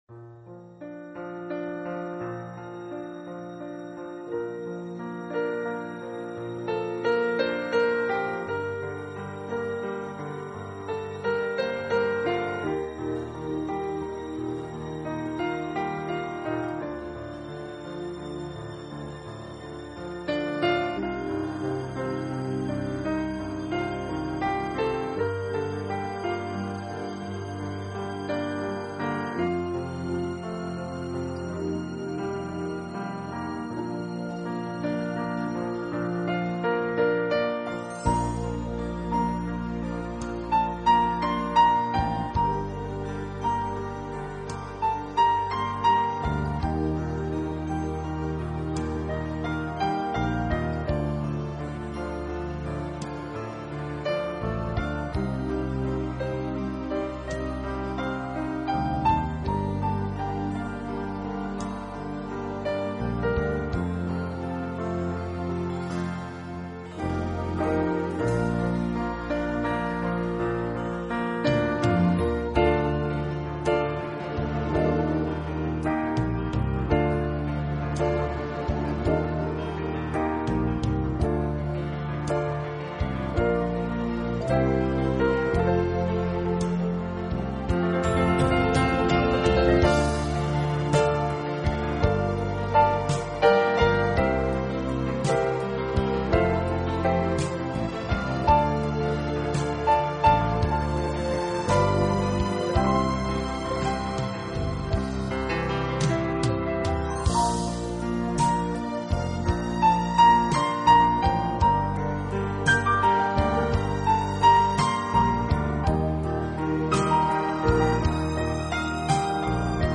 【浪漫钢琴】
音乐流派：Classical